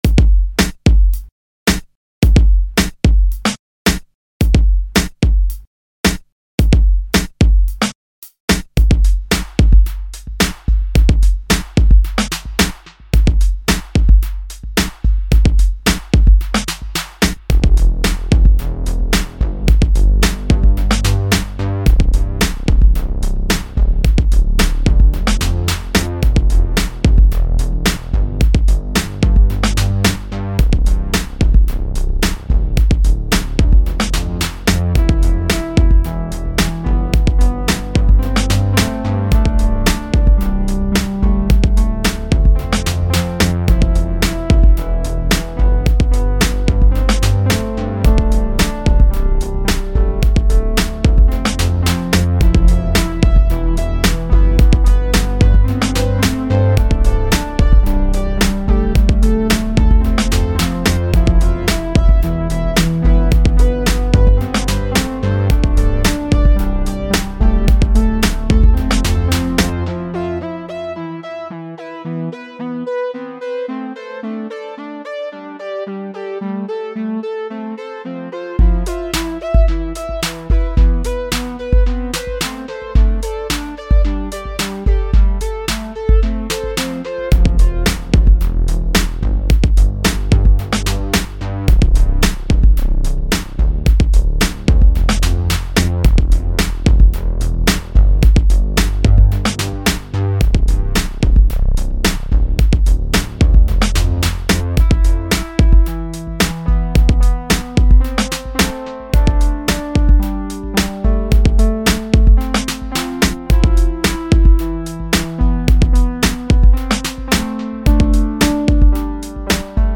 03:17 Electronica 4.5 MB